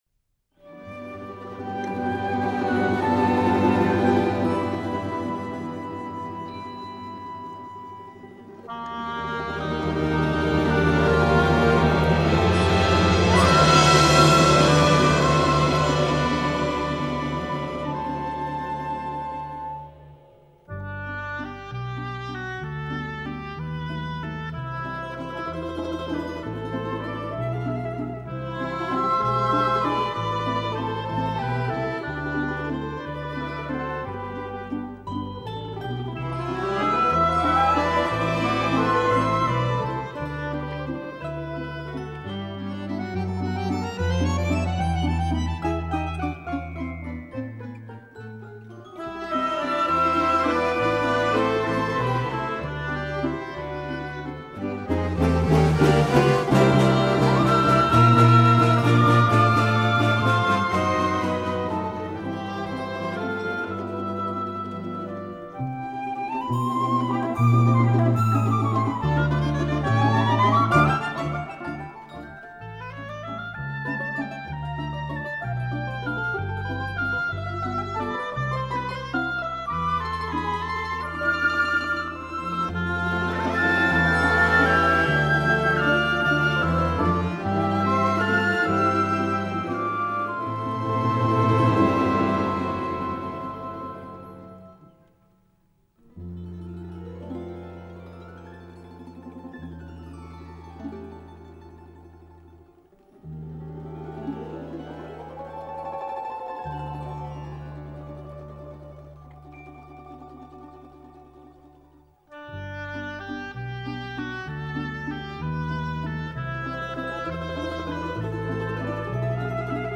Russian Folk Instruments Soloist's Band